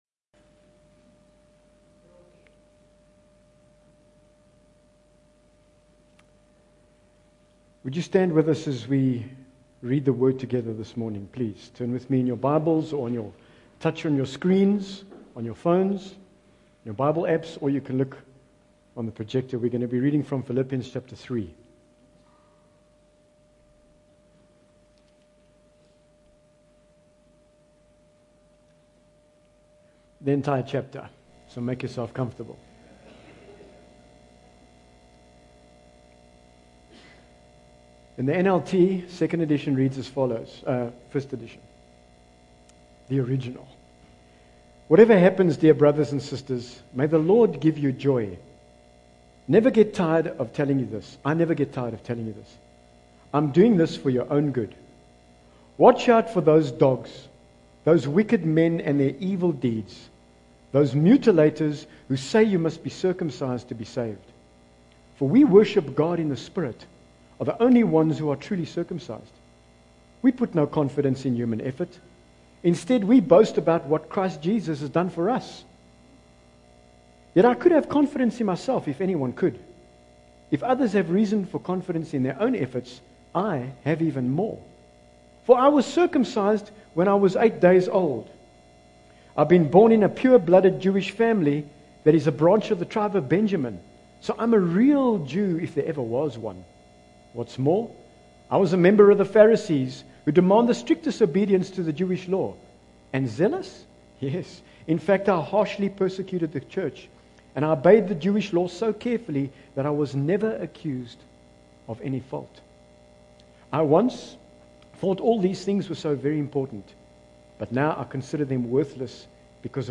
Bible Text: Philippians 3 | Preacher